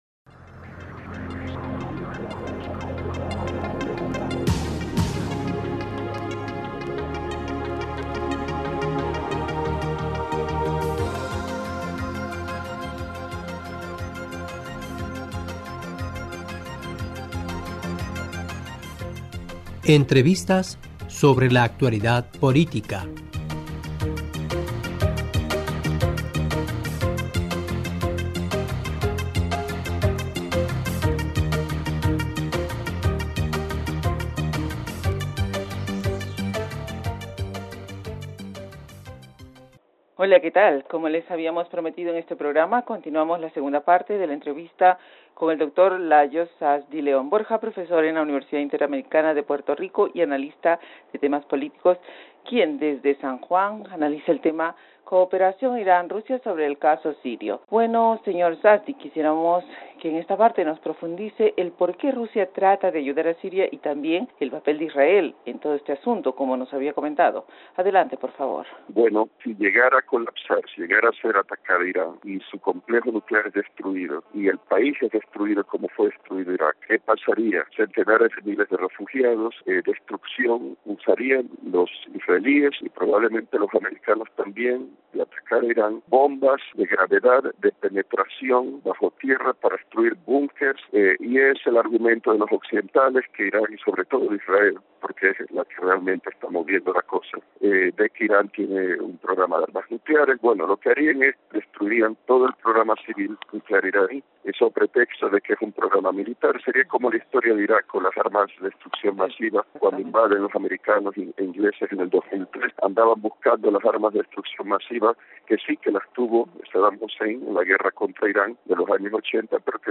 Cooperación Irán- Rusia sobre el caso sirio (II) - Pars Today